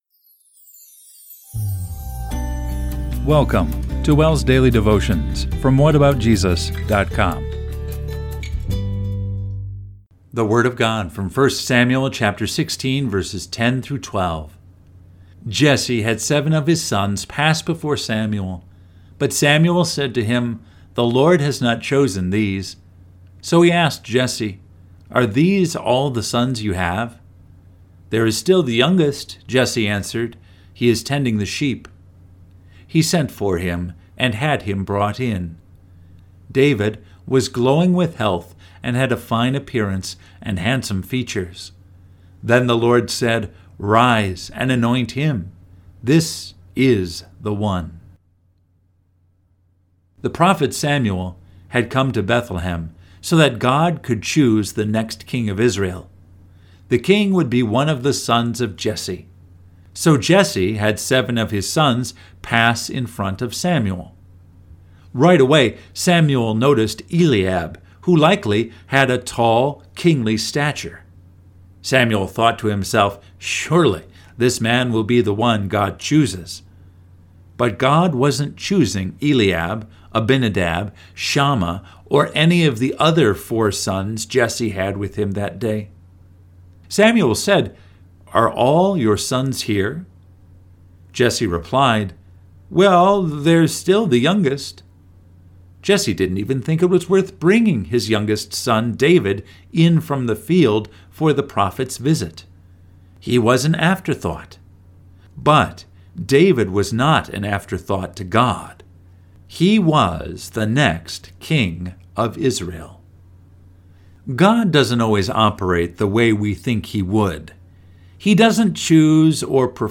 WELS delivers a daily podcast devotional message Monday through Friday each week.